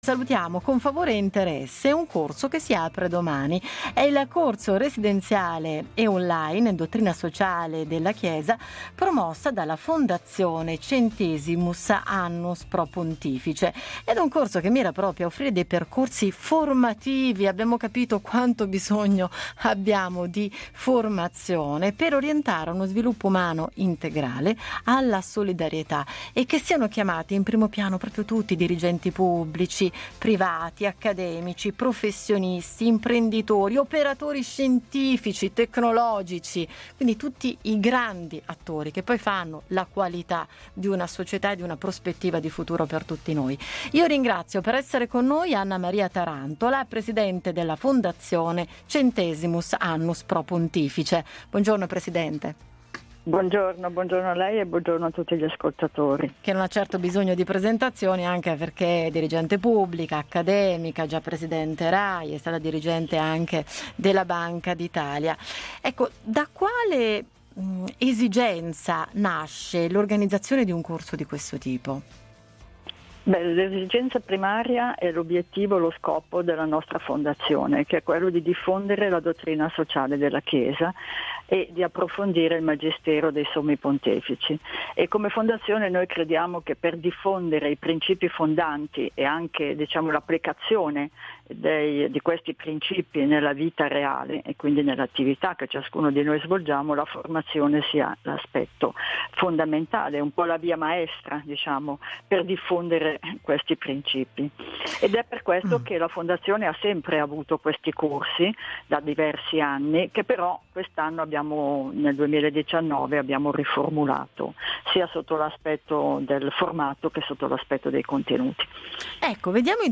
RADIO INBLU: RADIO INTERVISTA ALLA PRESIDENTE ANNA M. TARANTOLA 13 DICEMBRE 2019 – PRESENTAZIONE CORSO DSC EDIZIONE 2020